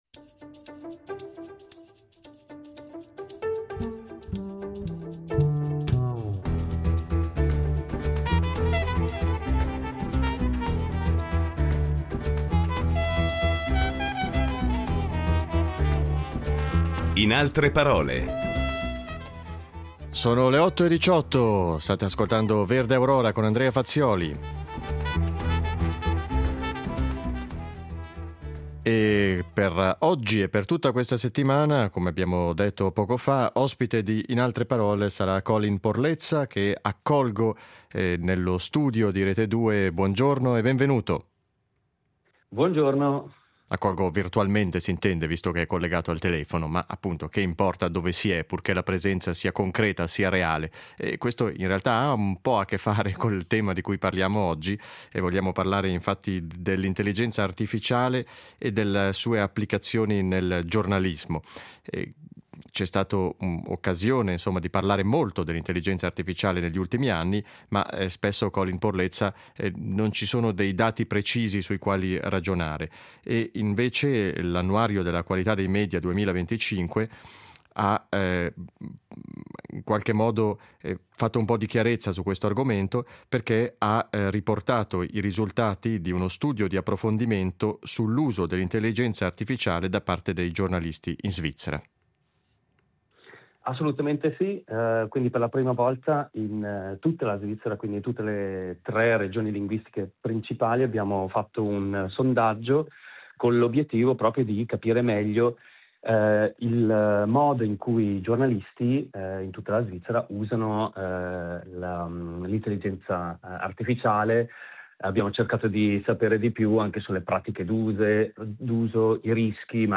Ospite della settimana il professore associato di giornalismo digitale